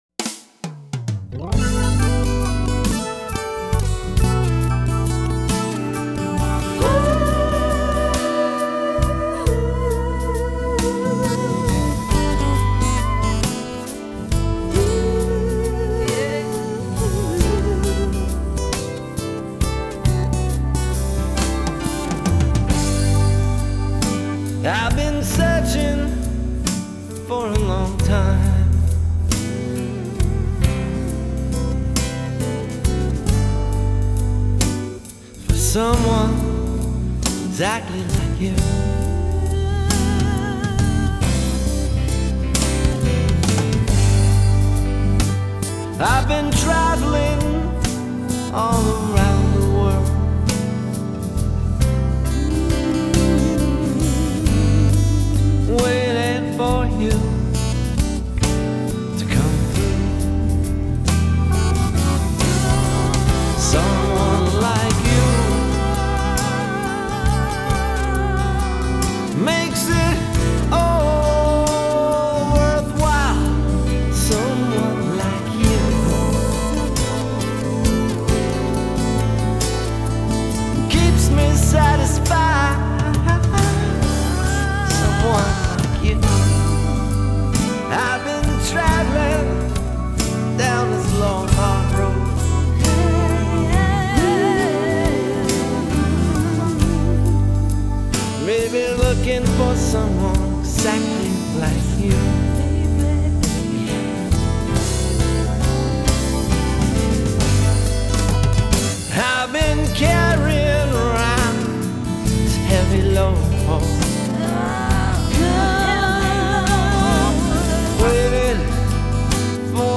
6 back up vocals